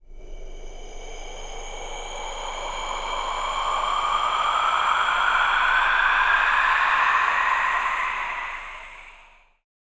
WIND COLD.wav